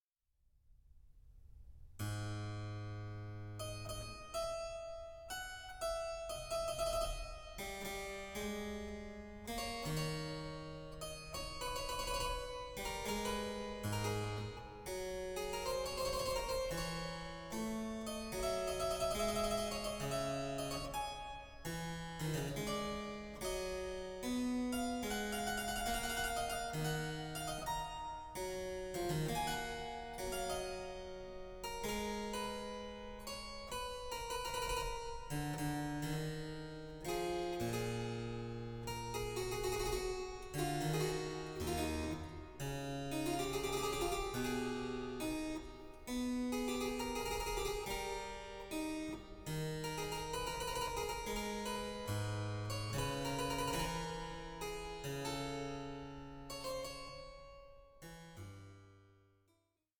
for flute, violin & basso continuo